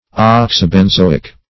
Search Result for " oxybenzoic" : The Collaborative International Dictionary of English v.0.48: Oxybenzoic \Ox`y*ben*zo"ic\, a. [Oxy (b) + benzoic.]